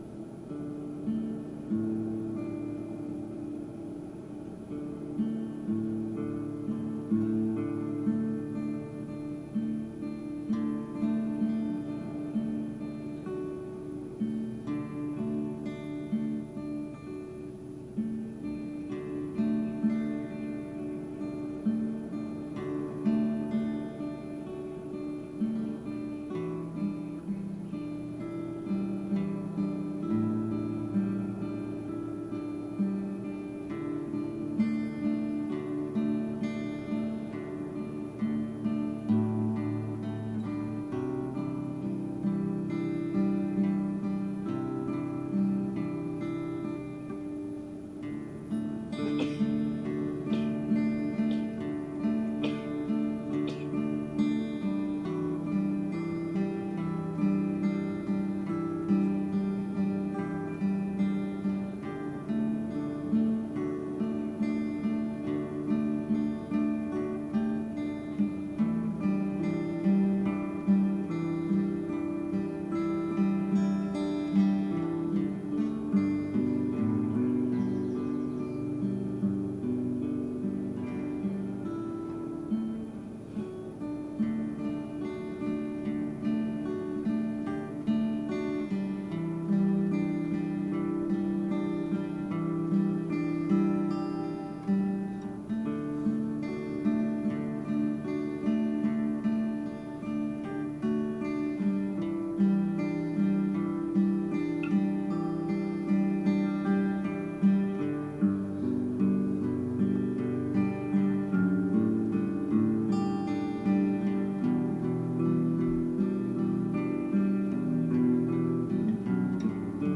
Venice - morning surf overdub